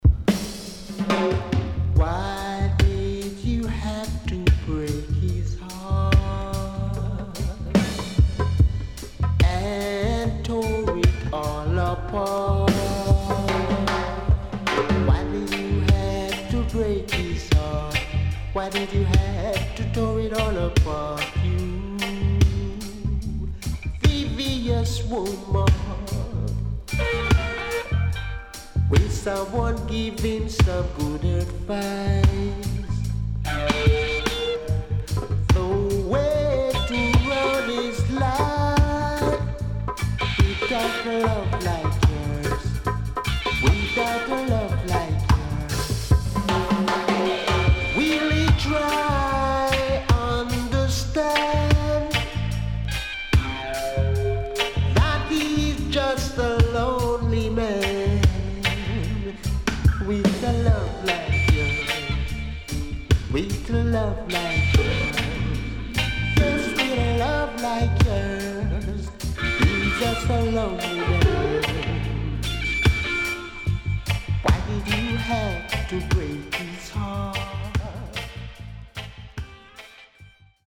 HOME > Back Order [VINTAGE LP]  >  KILLER & DEEP